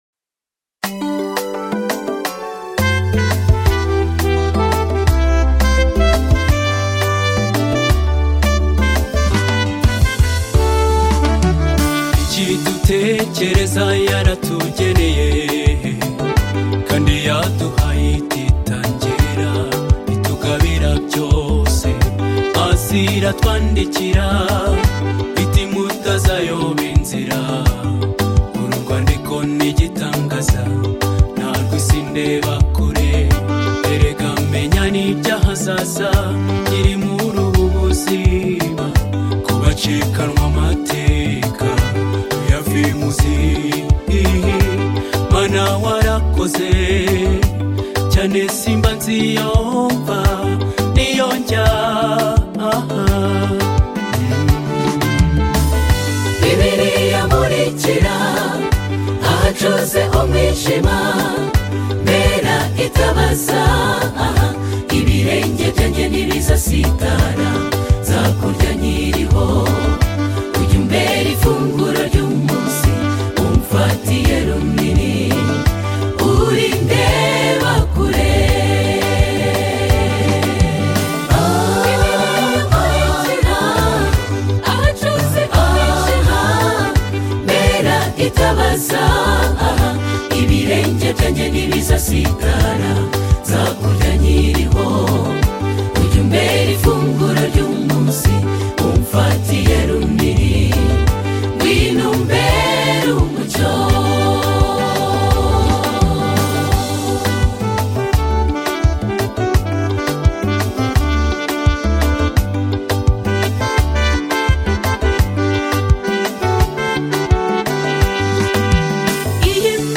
African Gospel Songs
The Rwanda the Seventh – day Adventist choir
soul lifting song